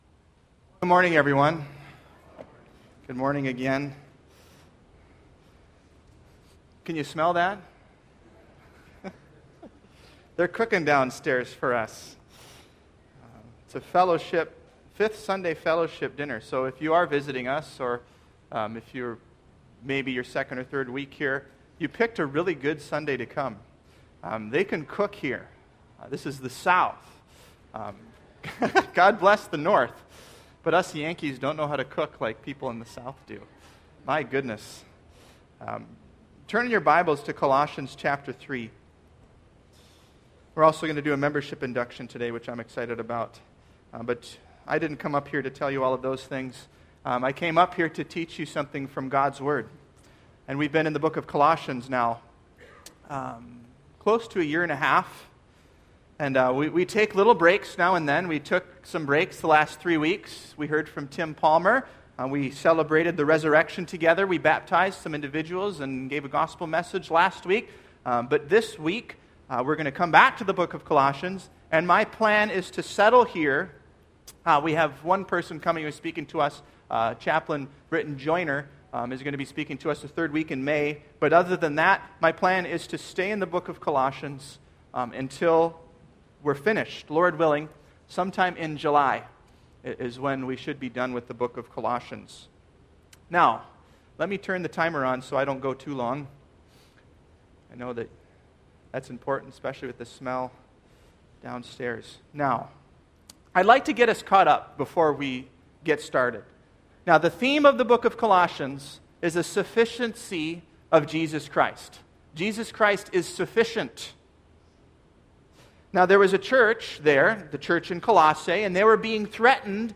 Sermons – Page 50 – Welcome to SNCCC